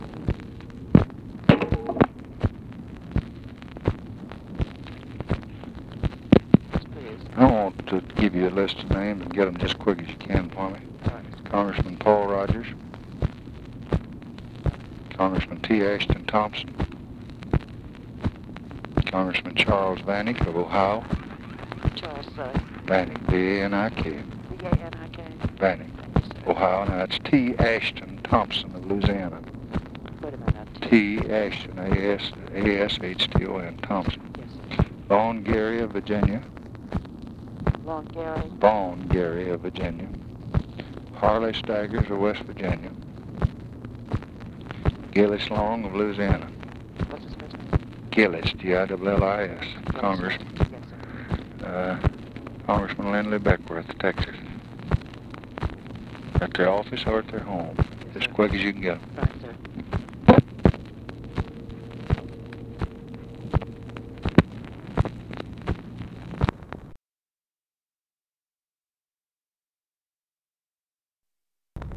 Conversation with TELEPHONE OPERATOR, April 7, 1964